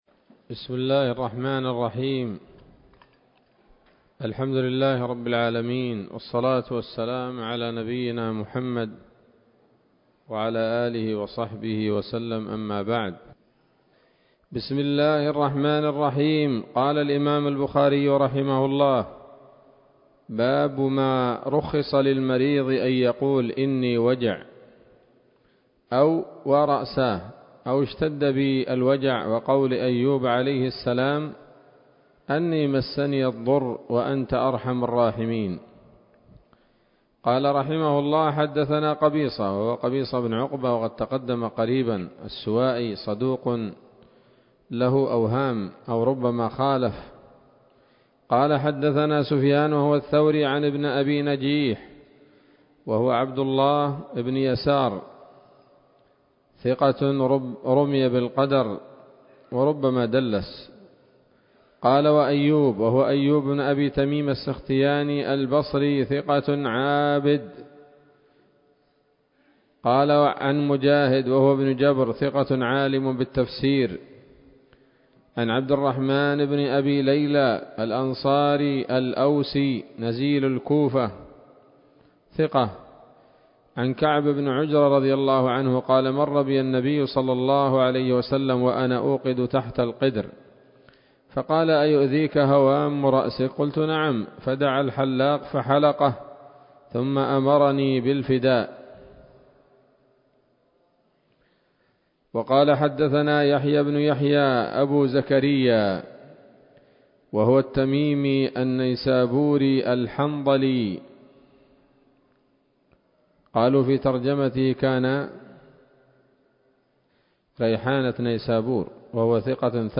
الدرس الثاني عشر من كتاب المرضى من صحيح الإمام البخاري